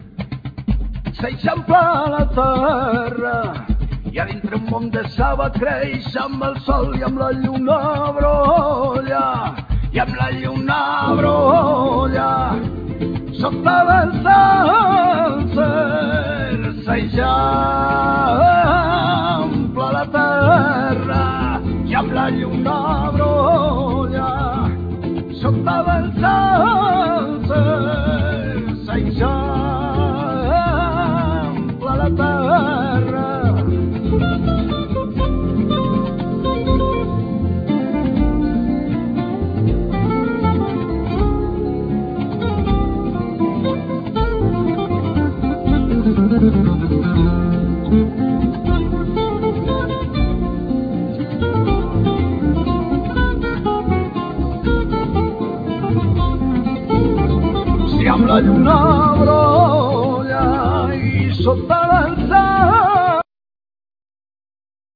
Vocal,Percussions
Spanish guitar,Mandola,Mandolin
Accodion
Dolcaina,Gralla,Cromorn,Gaida bulgara i xirimia
El.bass
Drums,Percussions
Flute
Loop,Programming
Double bass